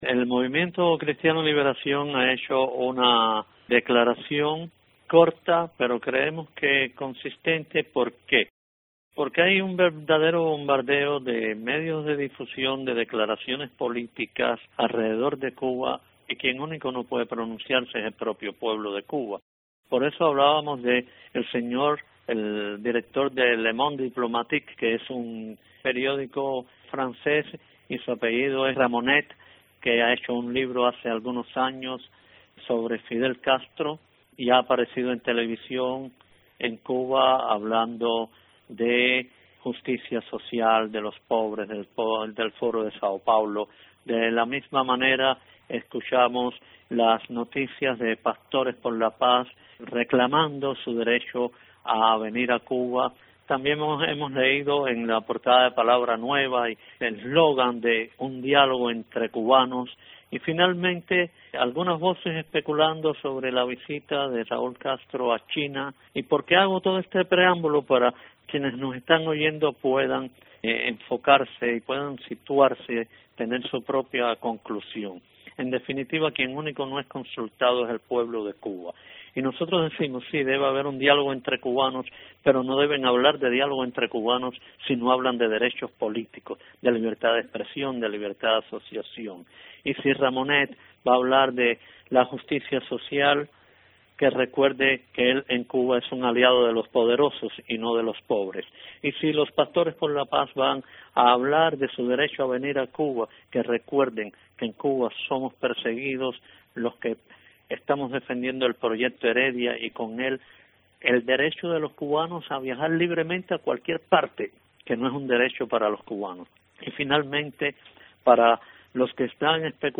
Declaraciones de Coordinador del Movimiento Cristiano Liberación Oswaldo Payá